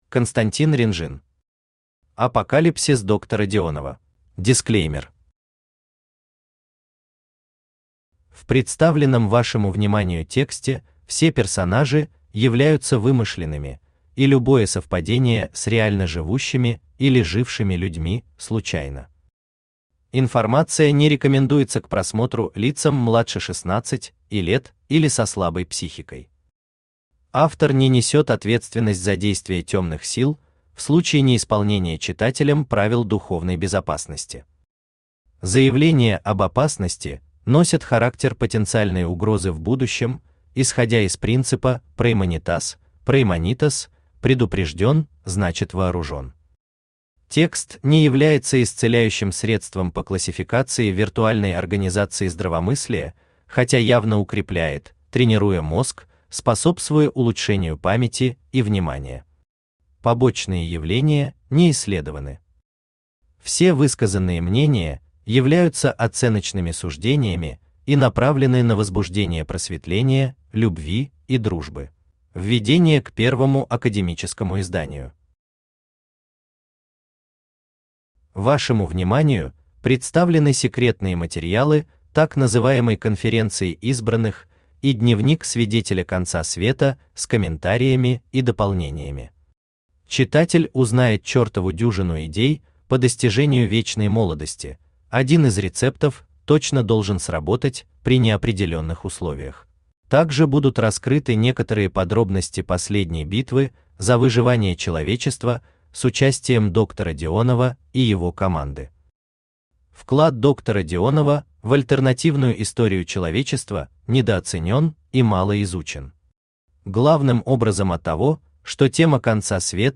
Аудиокнига Апокалипсис доктора Дионова | Библиотека аудиокниг
Aудиокнига Апокалипсис доктора Дионова Автор Константин Ренжин Читает аудиокнигу Авточтец ЛитРес.